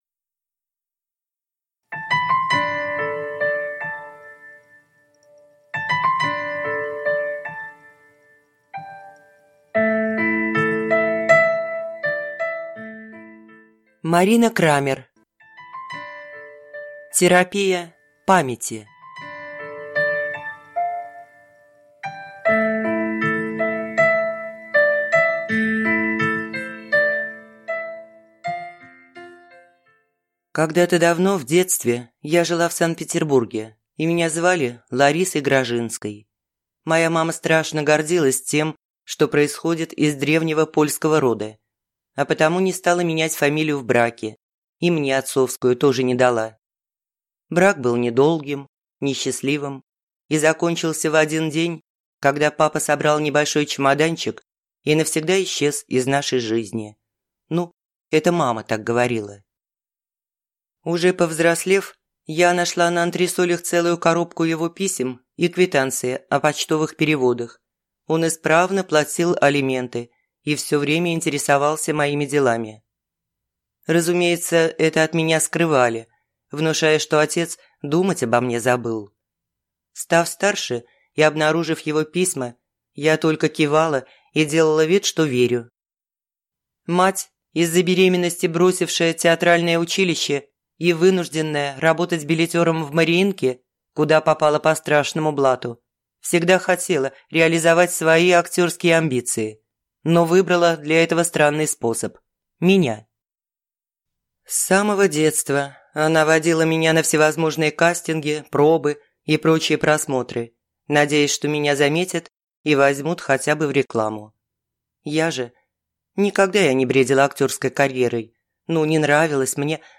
Аудиокнига Терапия памяти | Библиотека аудиокниг